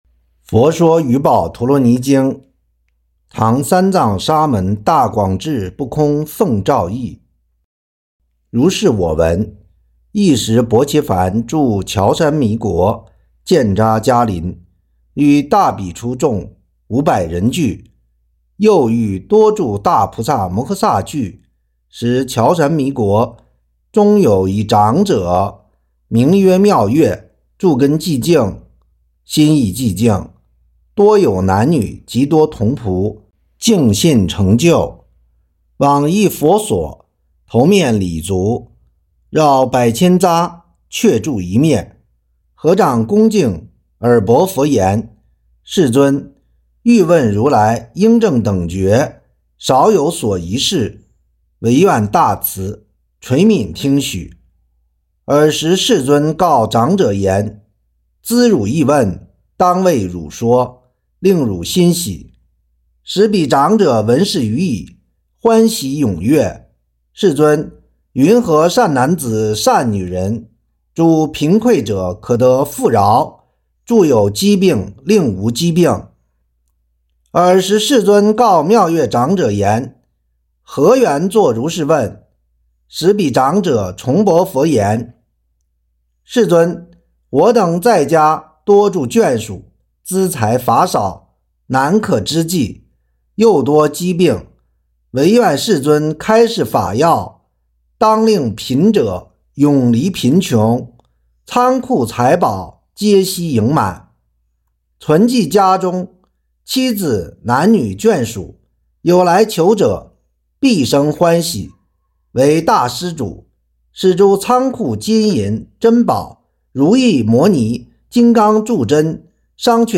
佛说雨宝陀罗尼经·原声版.mp3